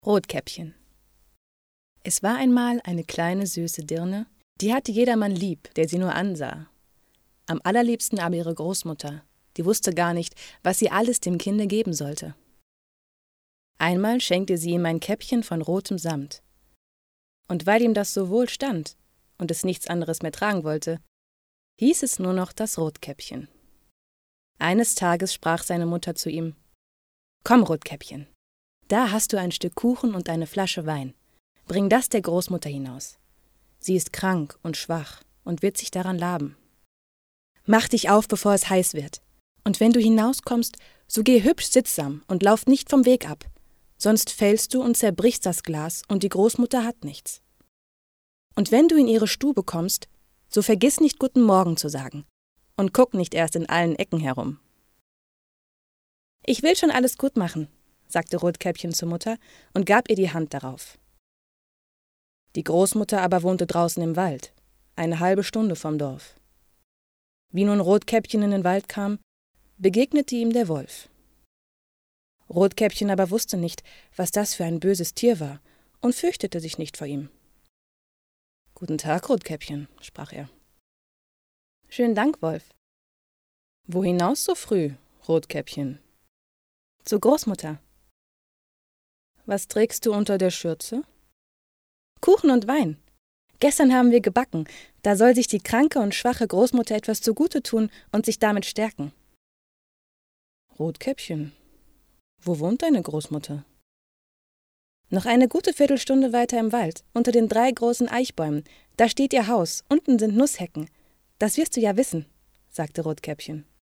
Junge Sprecherin deutsch
Sprechprobe: eLearning (Muttersprache):
Young German voice